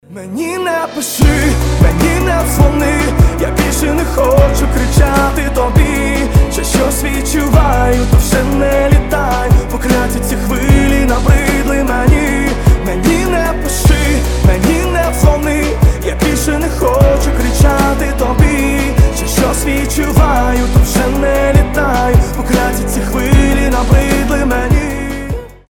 • Качество: 320, Stereo
грустные